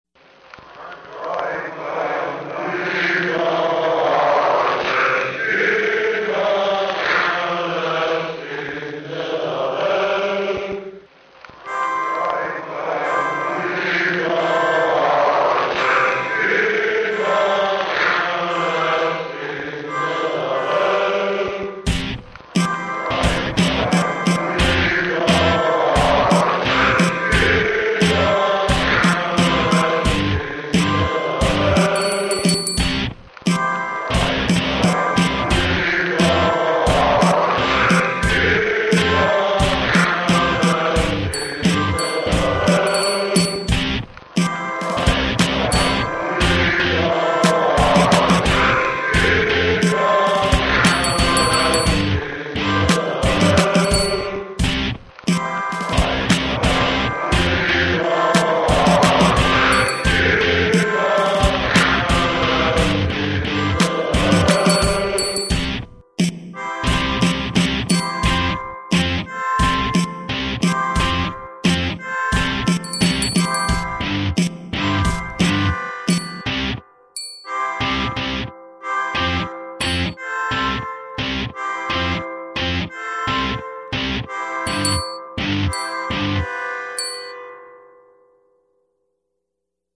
These songs were done using the "Track Swapping" method.